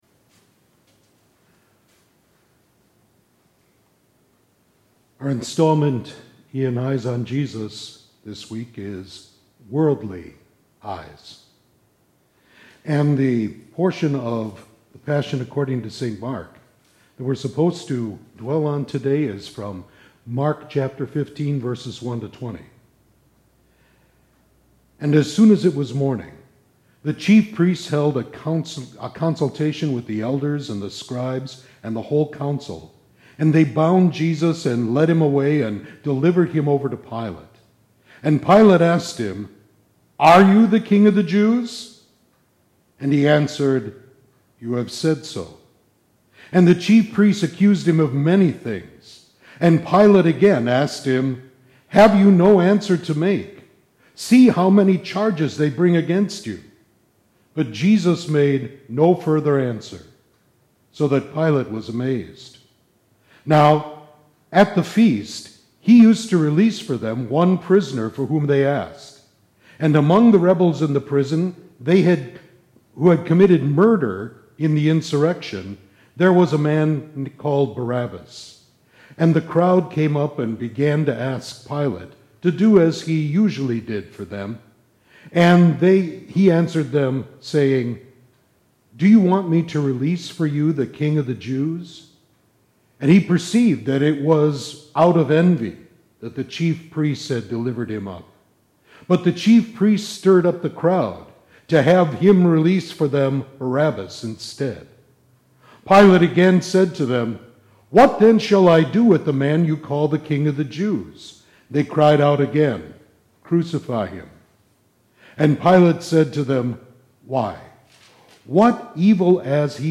Lent-Midweek-5-Sermon.mp3